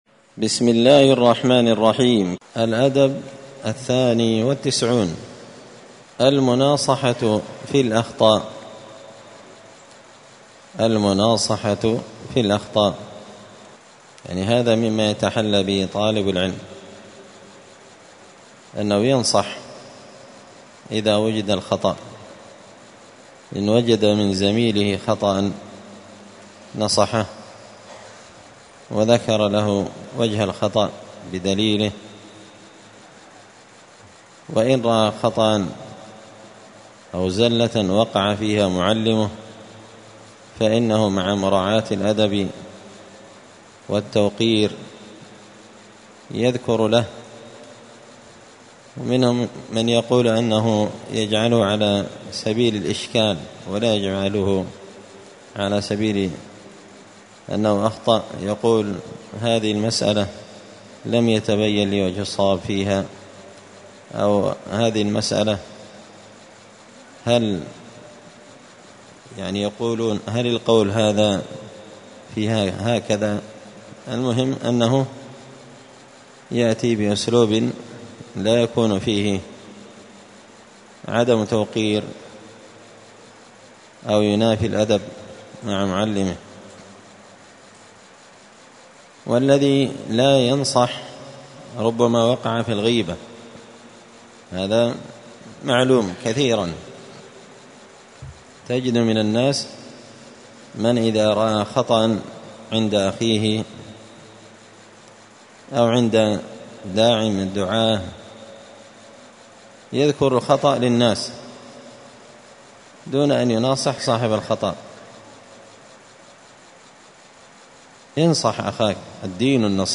الأحد 23 ربيع الأول 1445 هــــ | الدروس، النبذ في آداب طالب العلم، دروس الآداب | شارك بتعليقك | 80 المشاهدات